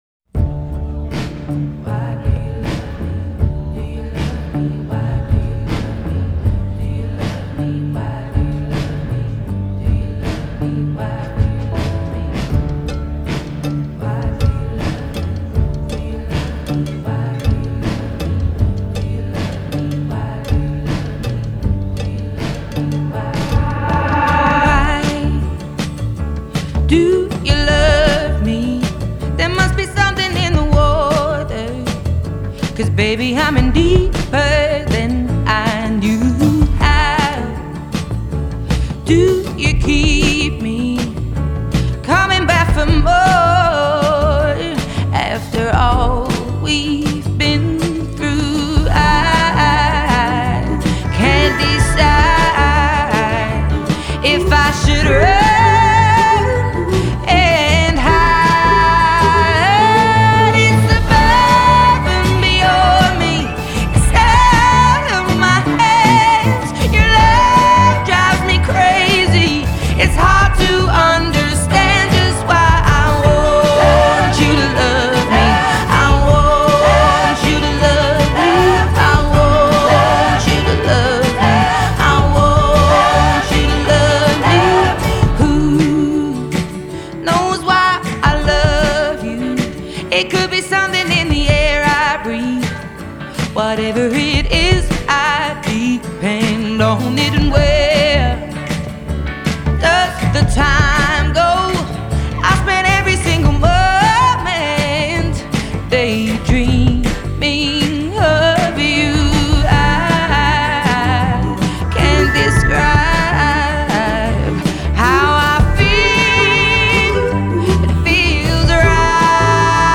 Genre: Pop,Blues